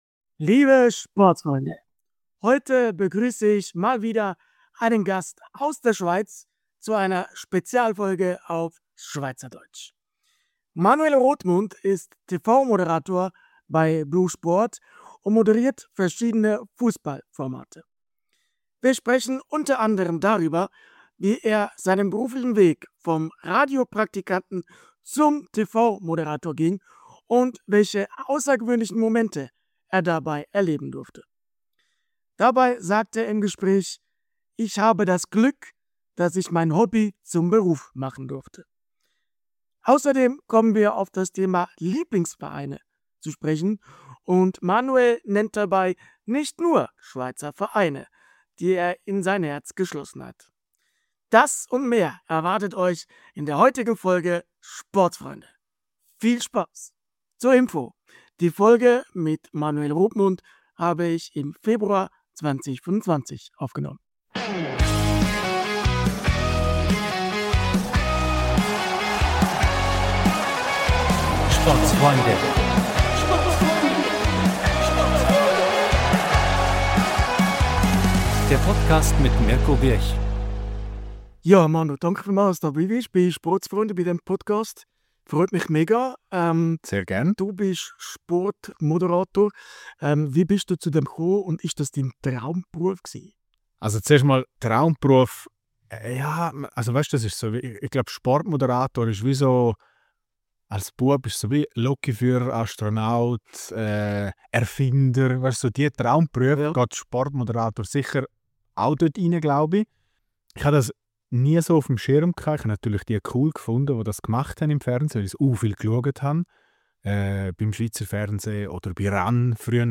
Spezialfolge auf Schweizerdeutsch! ~ Sportsfreunde Podcast